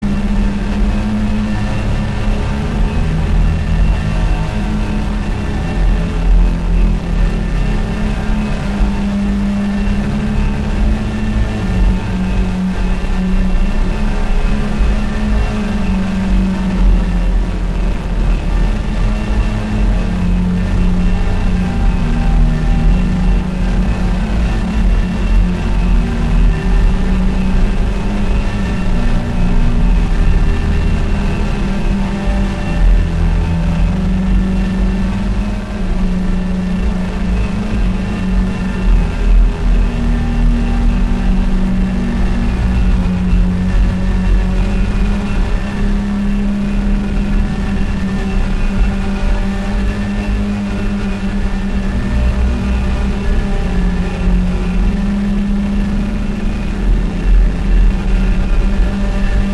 Some deepness. DN+AH+Bitwig Compressor